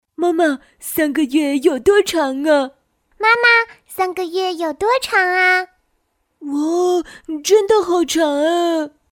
标签： 年轻
配音风格： 年轻 甜美 自然 可爱 亲切 温柔